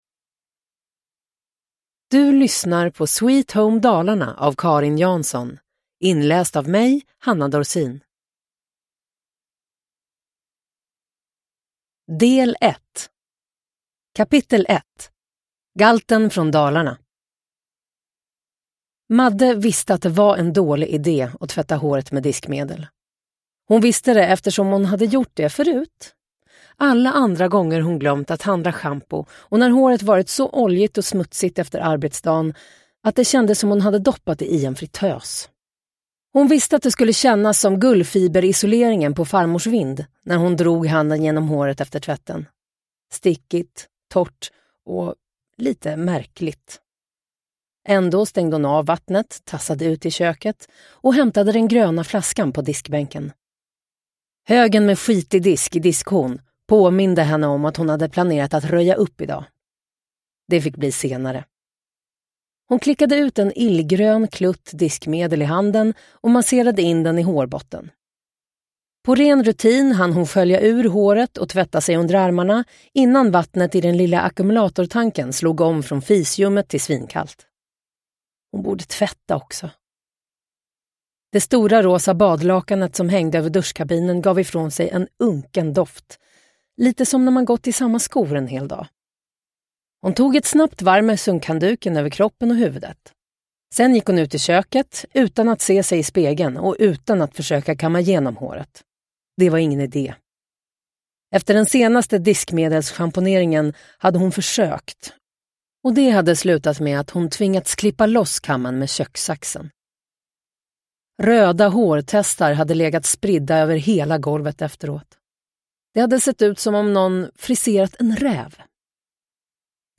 Sweet home Dalarna – Ljudbok – Laddas ner
Uppläsare: Hanna Dorsin